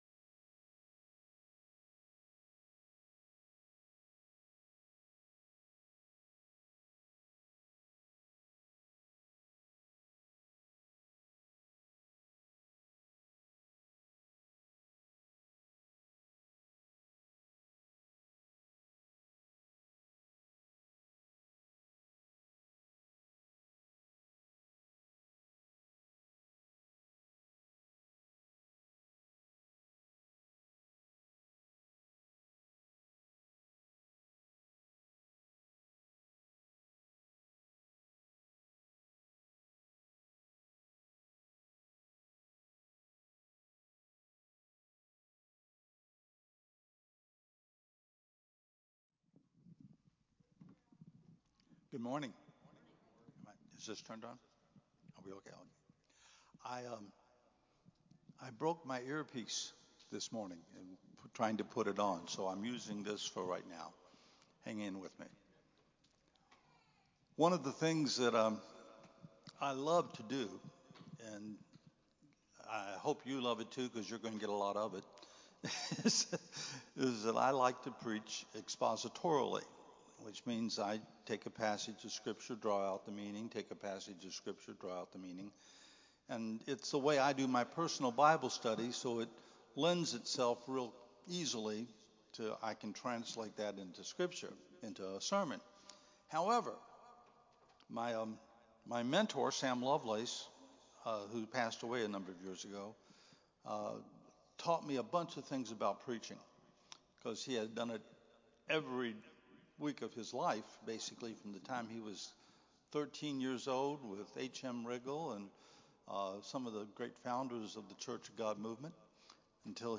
“Nobody is a Nobody” Sermon